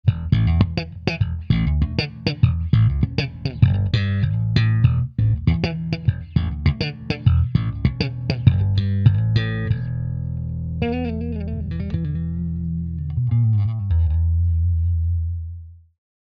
Punch BP 10/150 - Blues (Walking Groove)
bp10_slap_stick_0.mp3